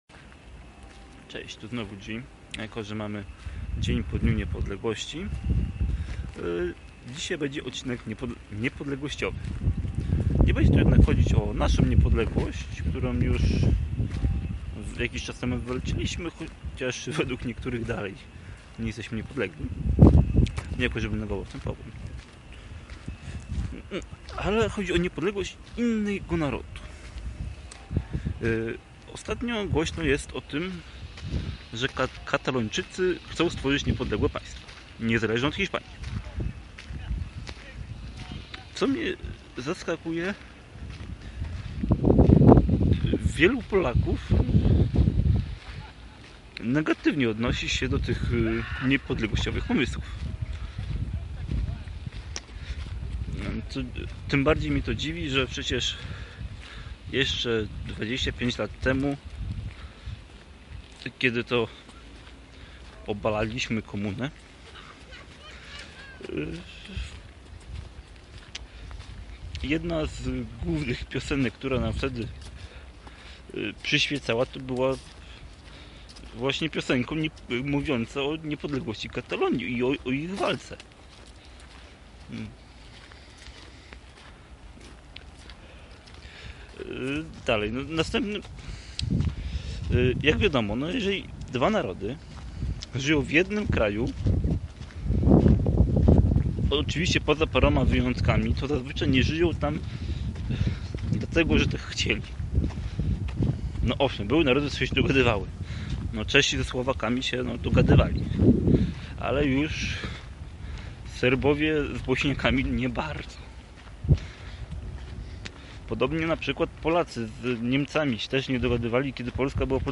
Vlog spacerowy - Niepodległa Katalonia